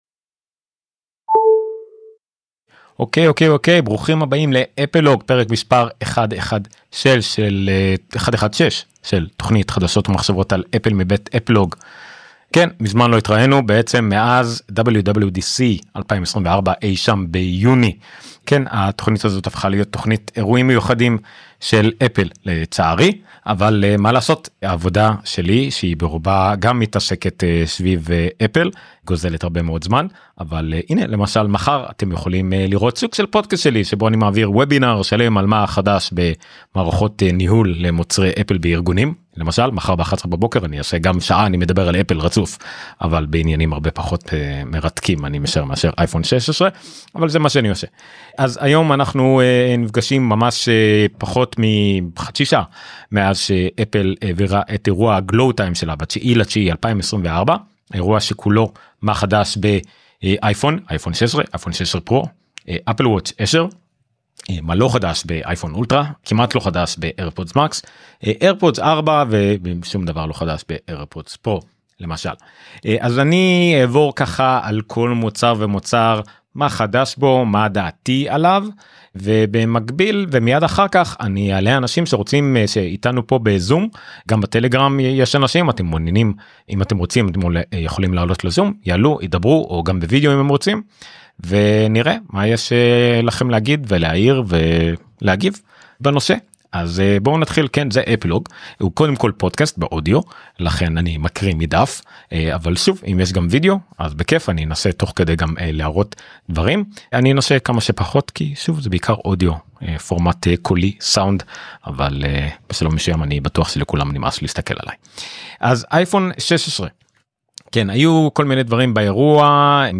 כמו כל אירוע אפל כבר 13 שנים – אפלוג מסכמים לכם את כל מה שחדש בשידור חי מיד לאחר תום האירוע.